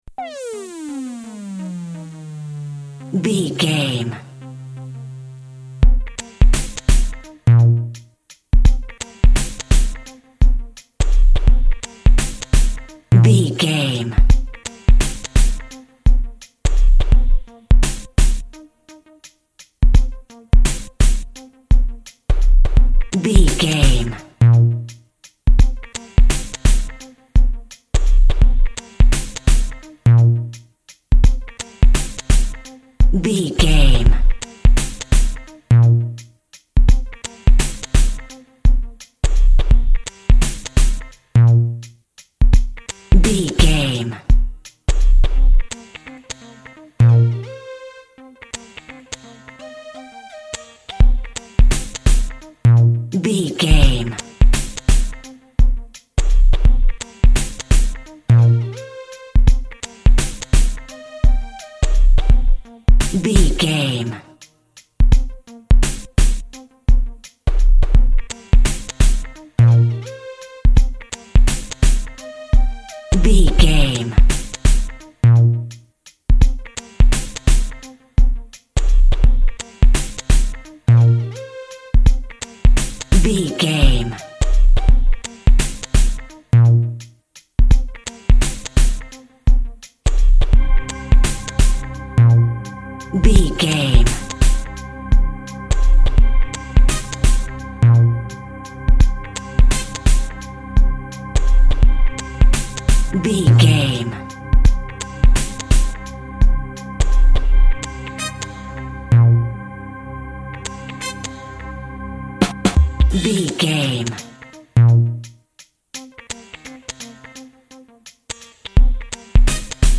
Urban Music Cue.
Aeolian/Minor
hip hop
synth lead
synth bass
hip hop synths